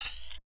cc - goosebumps perc.wav